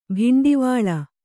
♪ bhiṇḍivāḷa